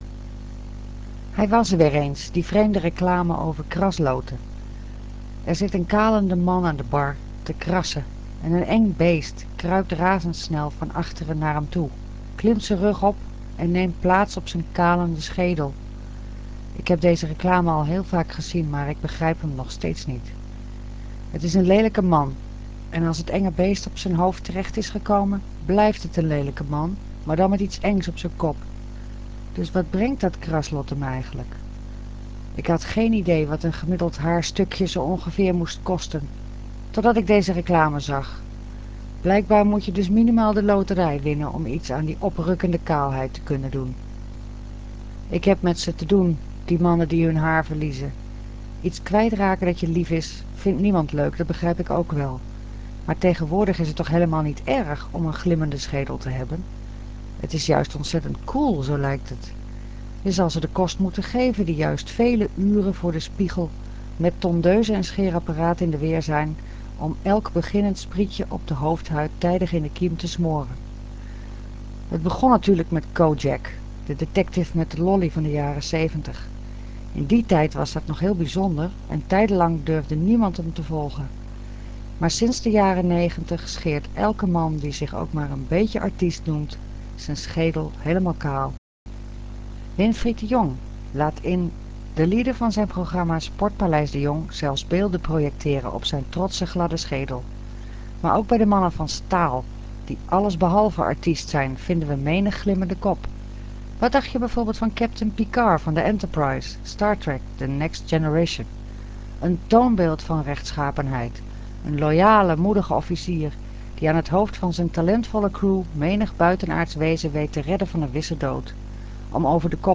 Lezen met de ogen dicht - beluisteren met RealAudio:
Kalende Mannen - voorgelezen